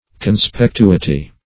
Meaning of conspectuity. conspectuity synonyms, pronunciation, spelling and more from Free Dictionary.